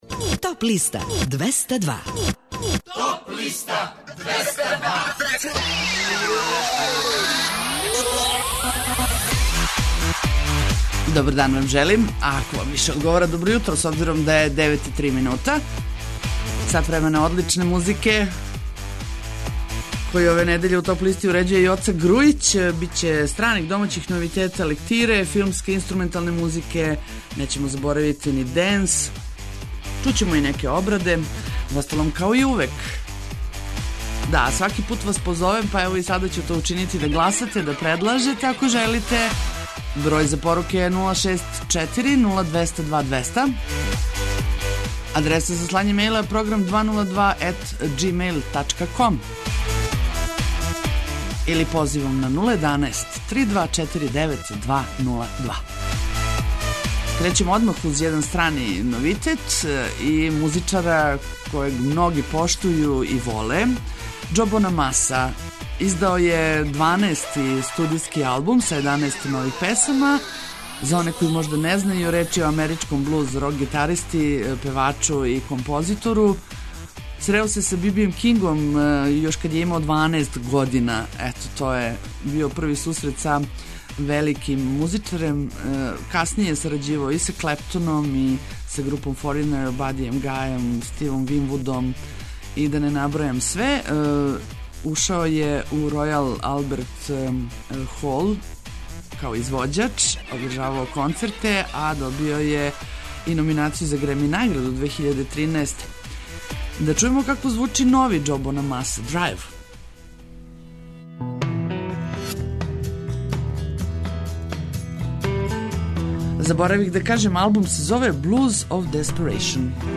Најавићемо актуелне концерте, подсетићемо се шта се битно десило у историји музике у периоду од 28. марта до 1. априла. Емитоваћемо песме са подлиста лектире, обрада, домаћег и страног рока, филмске и инструменталне музике, попа, етно музике, блуза и џеза, као и класичне музике.